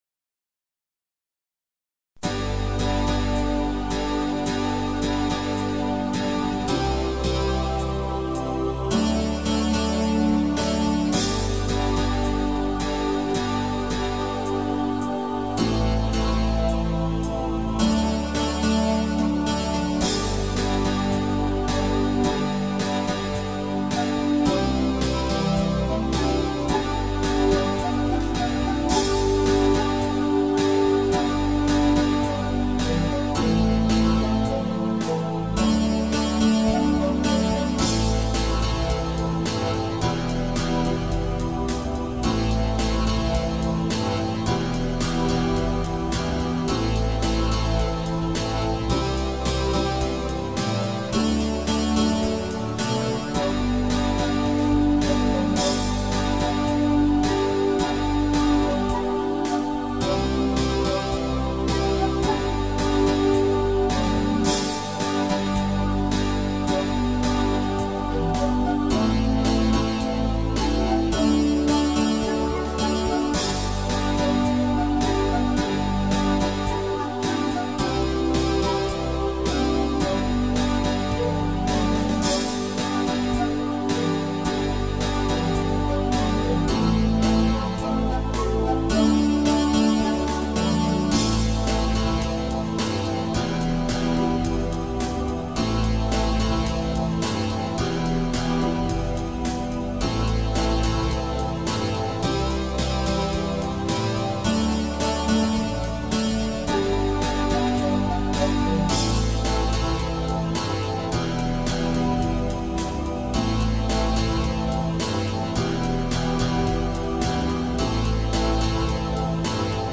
Музыкальная композиция